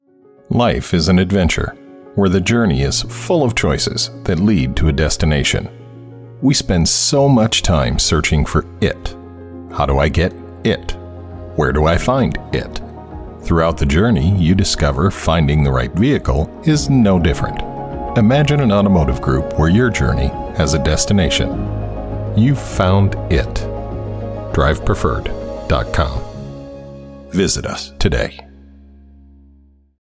Middle Aged